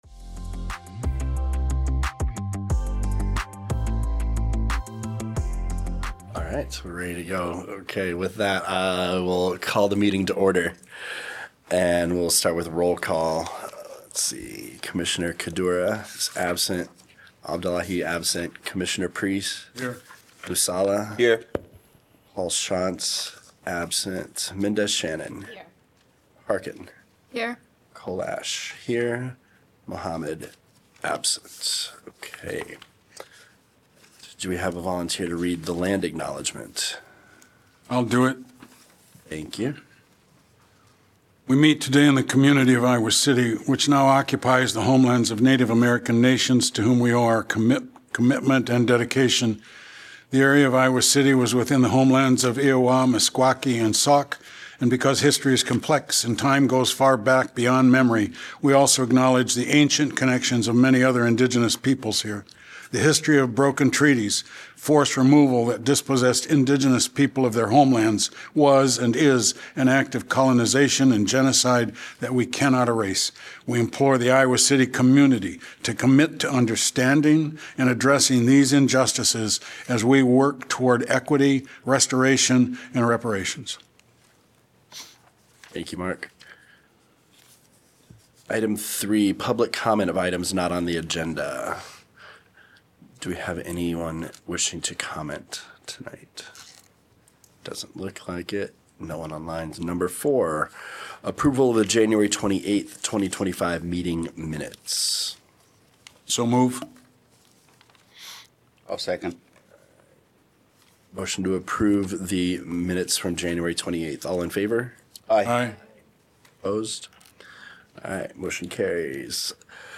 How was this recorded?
Regular monthly meeting of the Human Rights Commission.